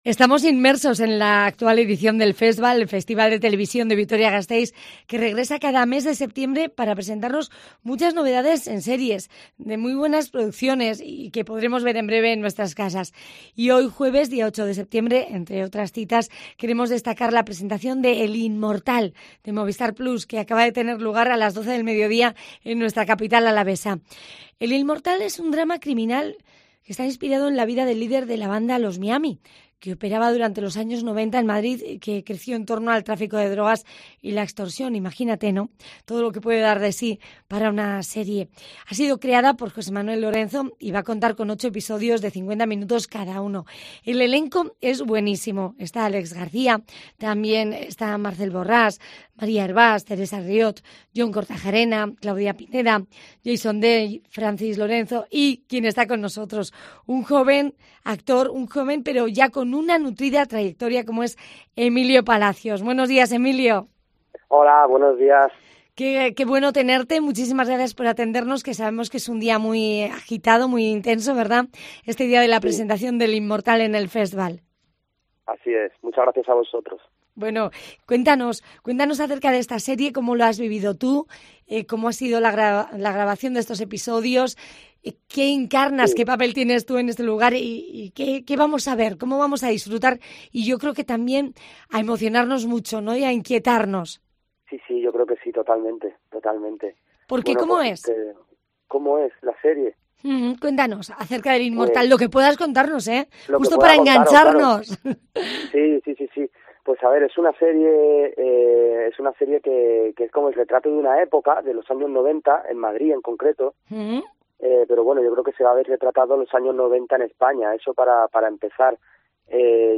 Puedes escuchar la entrevista completa clickando junto a la imagen en la que aparece el equipo de la serie en la sala 'The Garage', en Vitoria-Gasteiz.